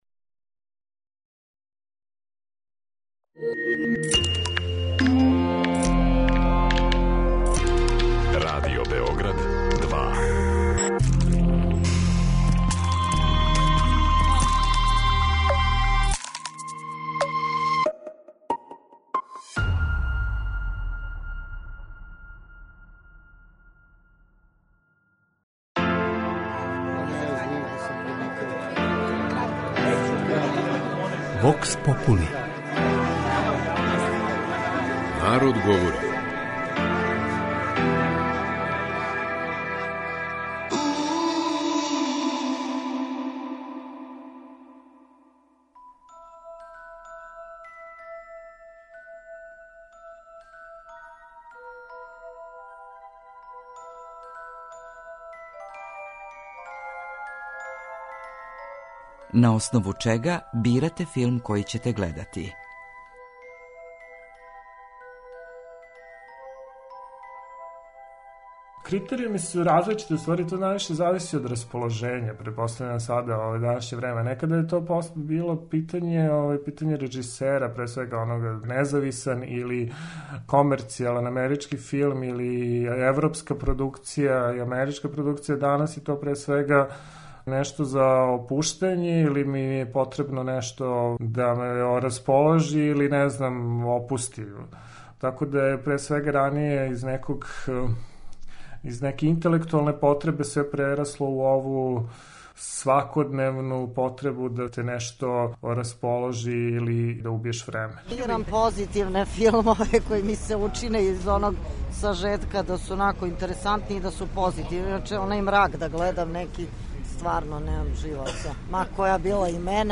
кроз кратке монологе, анкете и говорне сегменте